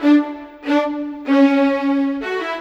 Rock-Pop 06 Violins 01.wav